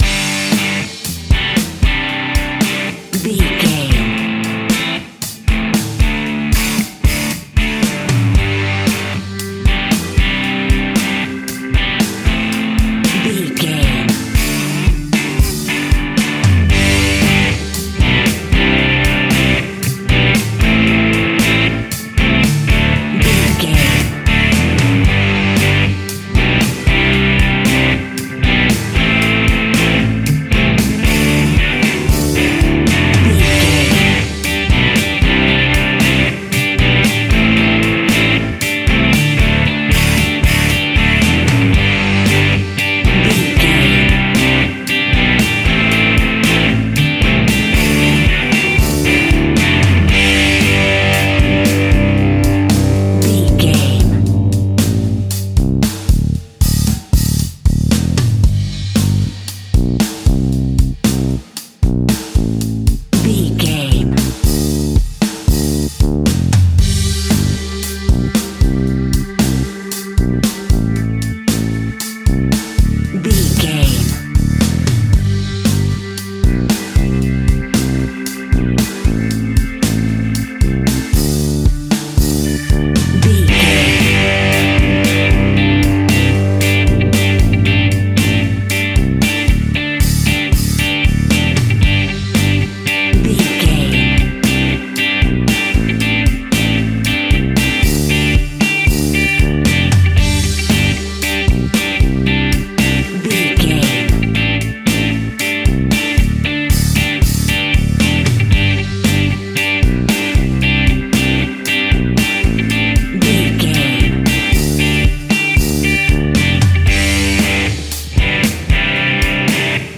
Ionian/Major
indie pop
fun
energetic
uplifting
acoustic guitars
drums
bass guitar
electric guitar
piano
organ